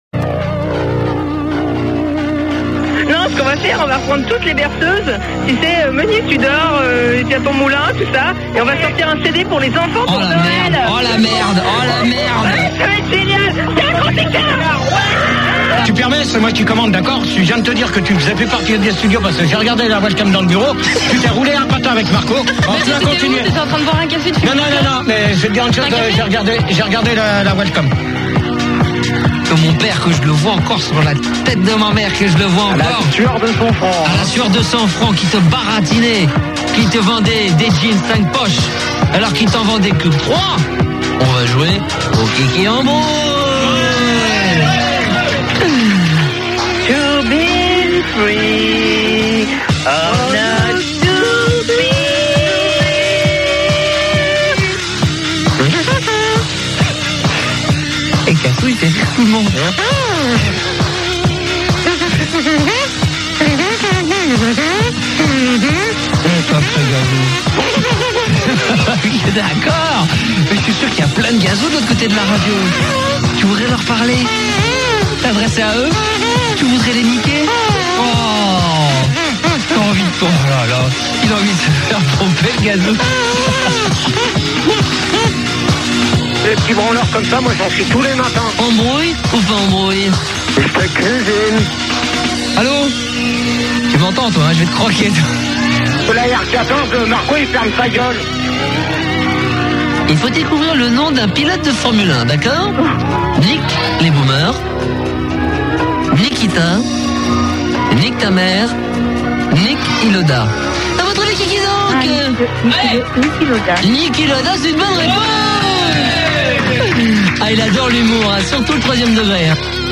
Extraits de l'émission de radio qui a duré de 1994 à 2001 sur Fun Radio sous des noms différents.